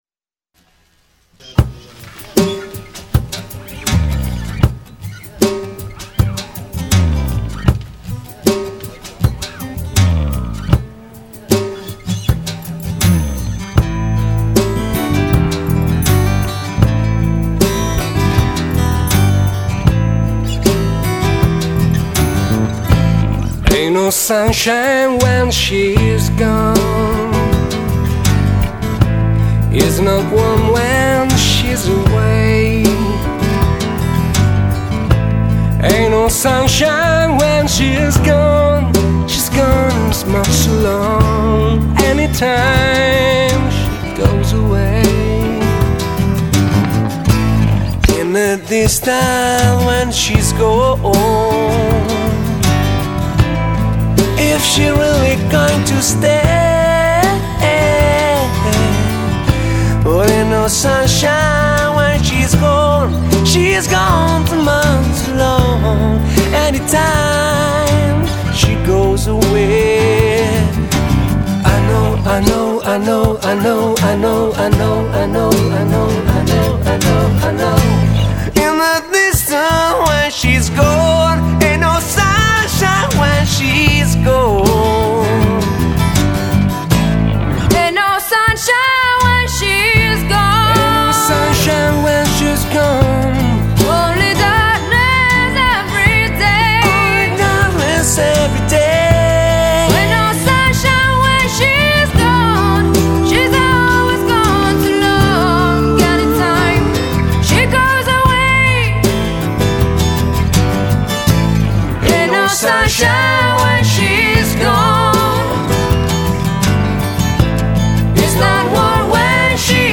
PopRockCountryBeat
cover di vario genere riarrangiate in chiave acustica